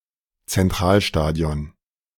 Red Bull Arena (German pronunciation: [ɹɛt ˈbʊl ʔaˌʁeːna]; formerly Zentralstadion German pronunciation: [tsɛnˈtʁaːlˌʃtaːdi̯ɔn]